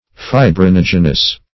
Search Result for " fibrinogenous" : The Collaborative International Dictionary of English v.0.48: Fibrinogenous \Fi`bri*nog"e*nous\, a. (Physiol.